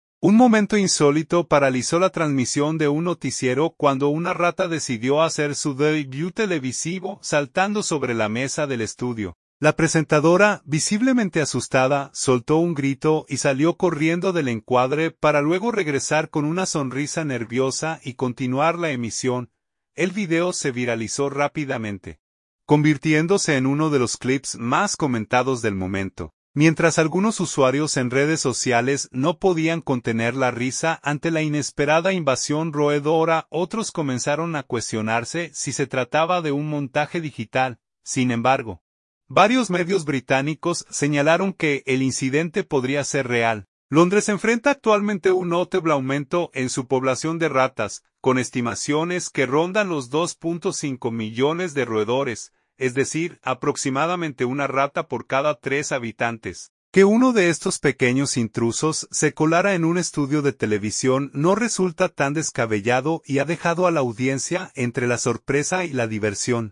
¡Increíble pero cierto! Una rata interrumpe en pleno noticiero en vivo en Londres
Un momento insólito paralizó la transmisión de un noticiero cuando una rata decidió hacer su debut televisivo, saltando sobre la mesa del estudio.
La presentadora, visiblemente asustada, soltó un grito y salió corriendo del encuadre, para luego regresar con una sonrisa nerviosa y continuar la emisión.